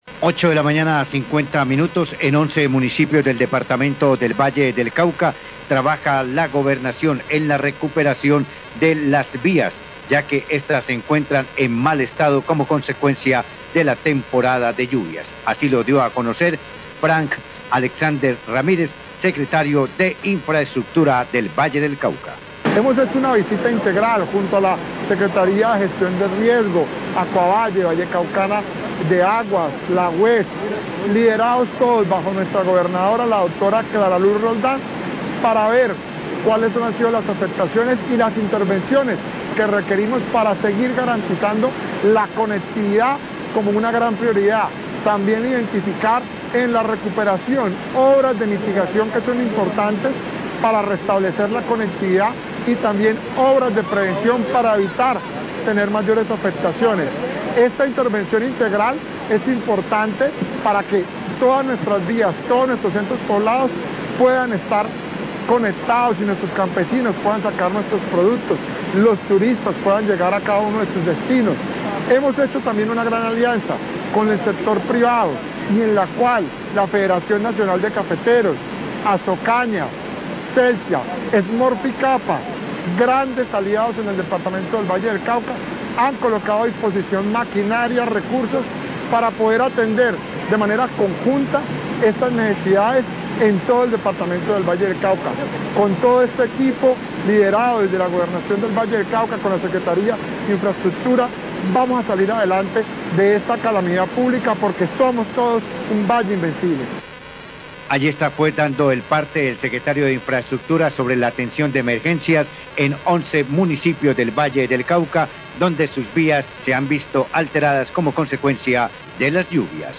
Radio
Secretario de Infraestrcutura, Frank Ramirez, habló sobre los esfuerzos que se hace desde la Gobernación del Valle para recuperar las vías que se han visto afectadas por las lluvias en 11 municipios.